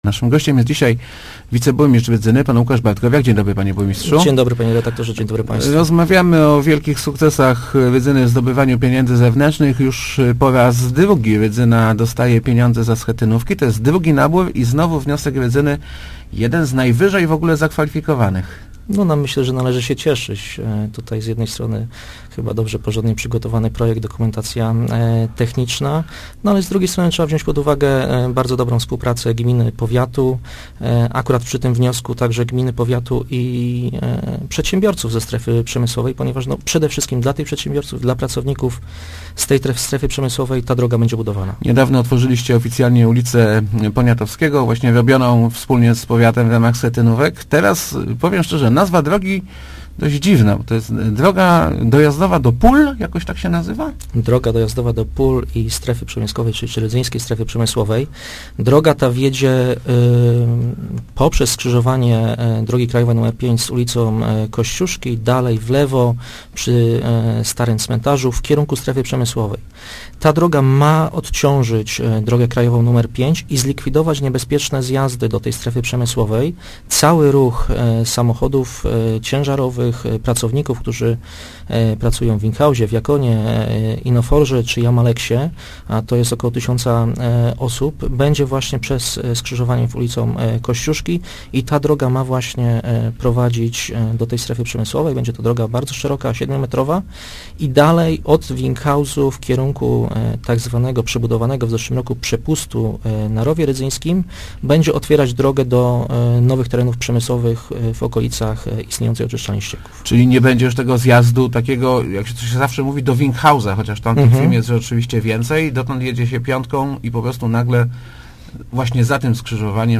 bartkowiak80.jpg„Schetynówka”, którą zbudujemy, jest nam niezbędna dla przyciągnięcia inwestorów – mówił w Rozmowach Elki wiceburmistrz Rydzyny Łukasz Bartkowiak. Droga doprowadzi ruch zarówno do już istniejących, jak i planowanych terenów przemysłowych.